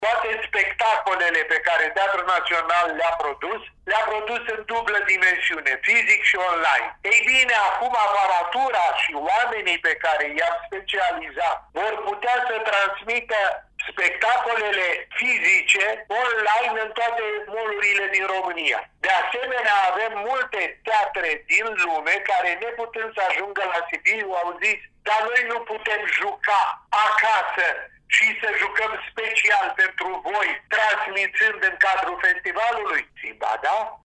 Într-un interviu acordat Europa FM, președintele FITS, Constantin Chiriac, spune că, în ciuda pandemiei, ediția din acest an, desfășurată între 20 și 29 august, va fi una dintre cele mai ample.